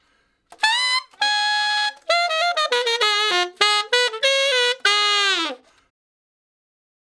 068 Ten Sax Straight (Ab) 11.wav